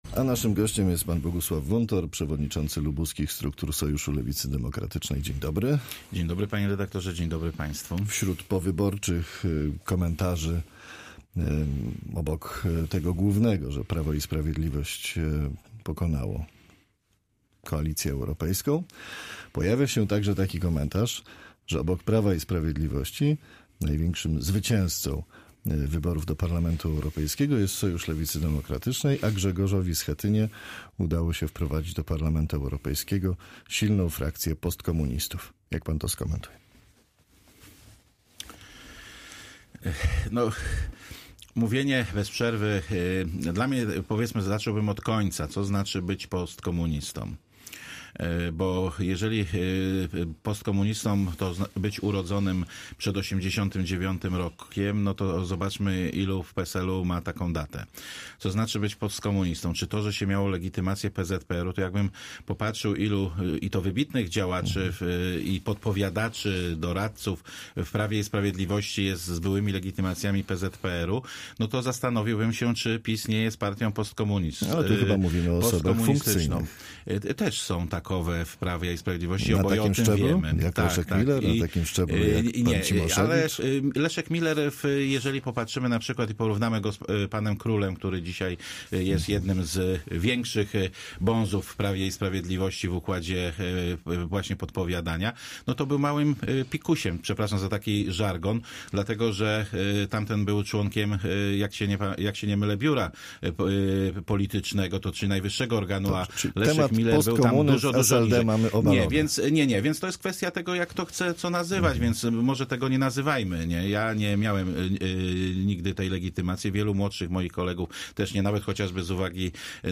Z przewodniczącym lubuskich struktur SLD rozmawia